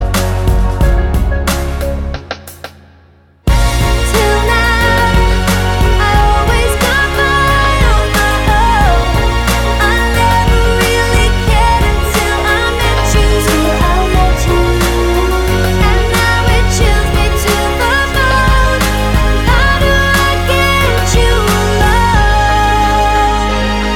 With Rapper Dance 3:09 Buy £1.50